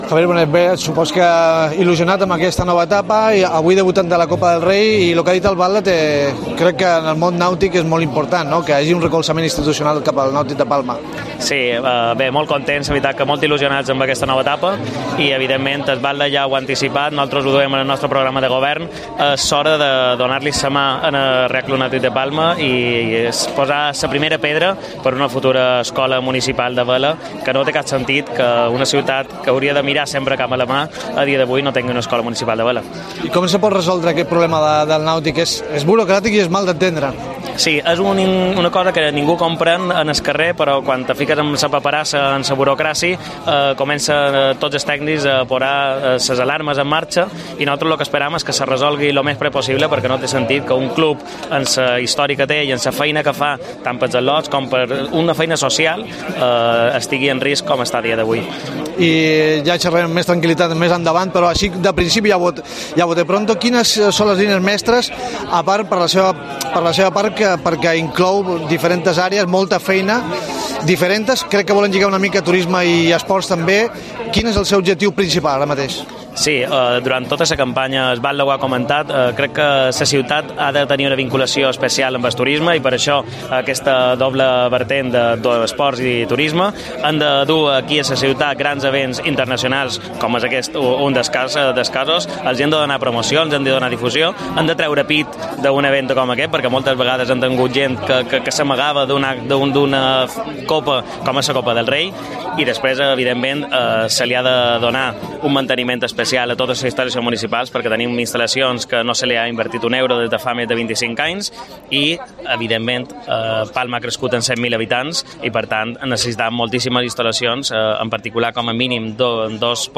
Estrena cargo hace apenas unos días, el nuevo teniente de alcalde de Turismo, cultura y deportes de Palma, Javier Bonet, habla de sus objetivos para la nueva etapa y apoya la 41 edición de la Copa del Rey Mapfre de Vela. Quiere impulsar una escuela municipal de vela junto al Real Club Náutico de Palma.